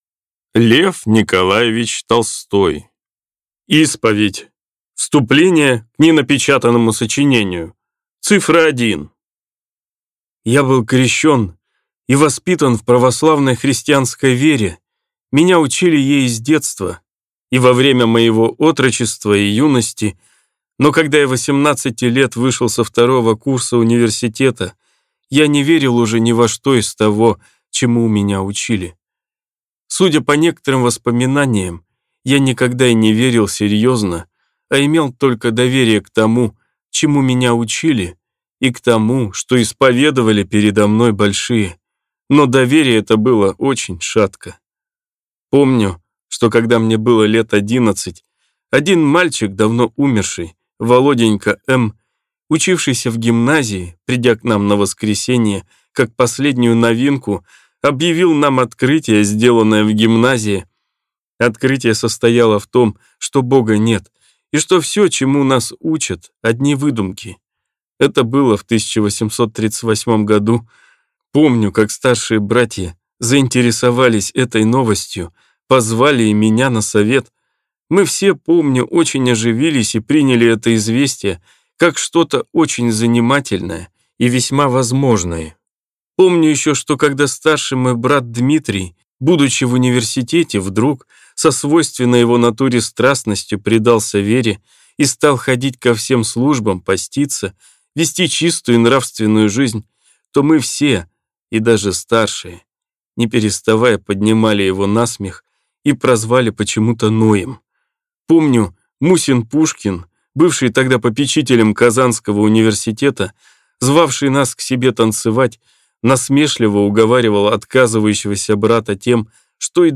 Аудиокнига Исповедь | Библиотека аудиокниг